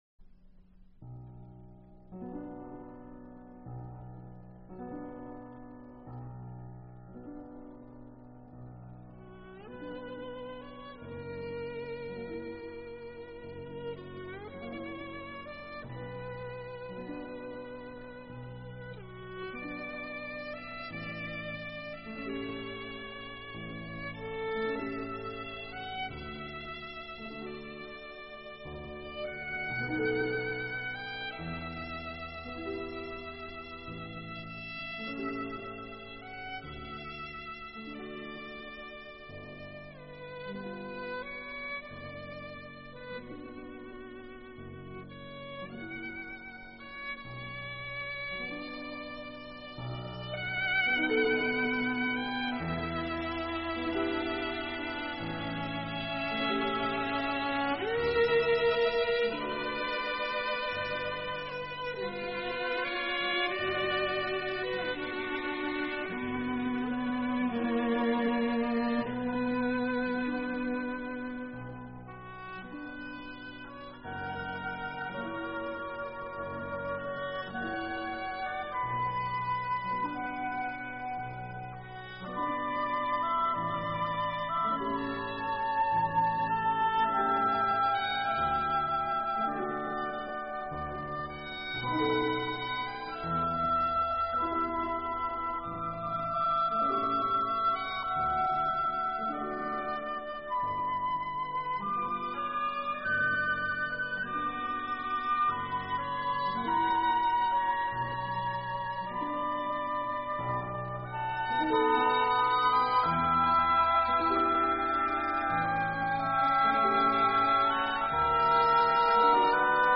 В этой музыке слышится до боли всем нам знакомое